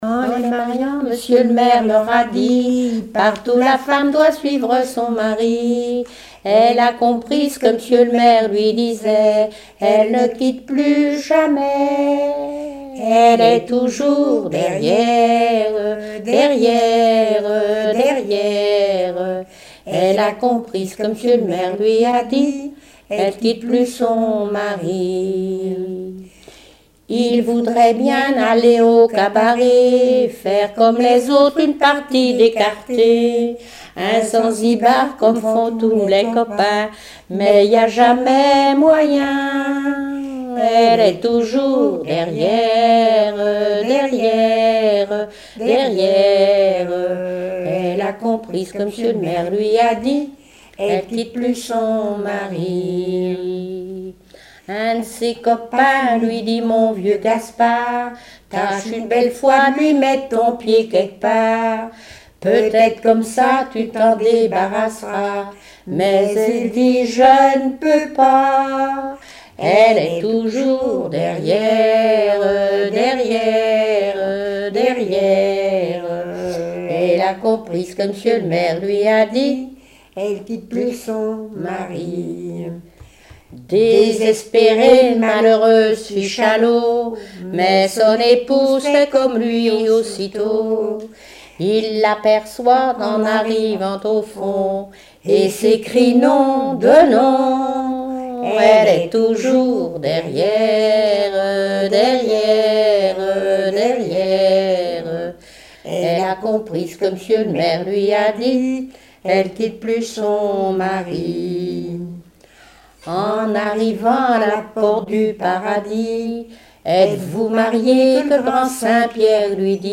Genre strophique
Chansons et témoignages
Pièce musicale inédite